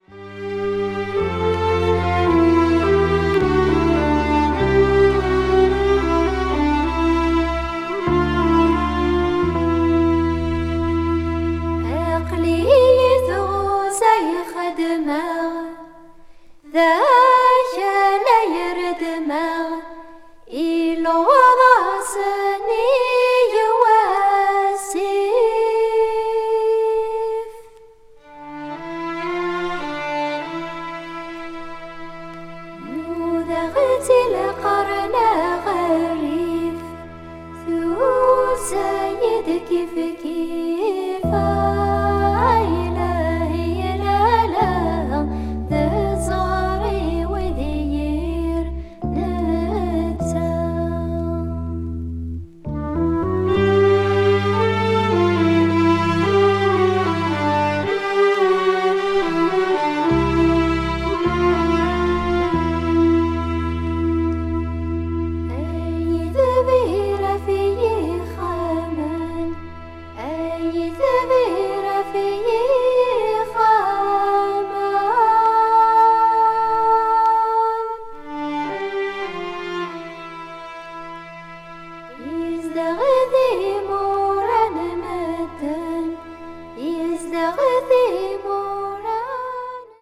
North African-Algerian vocal group
algeria   arab   ethnic   north africa   world music